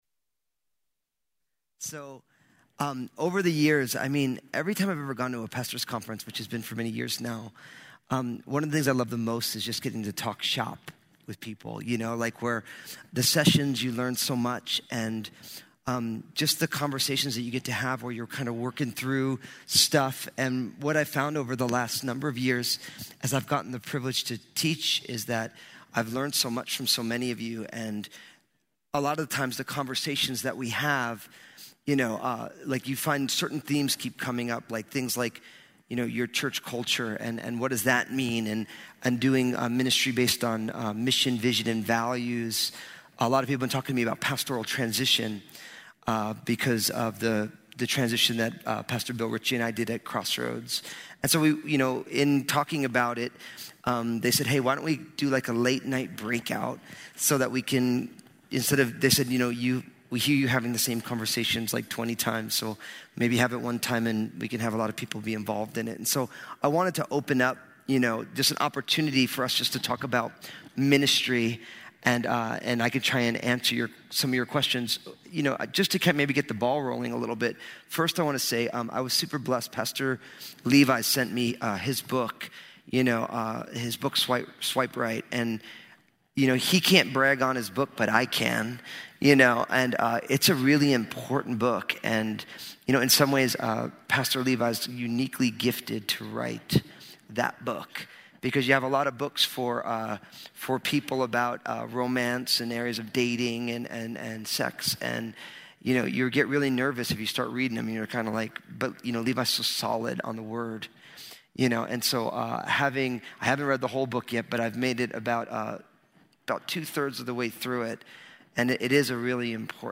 Southwest Pastors and Leaders Conference 2017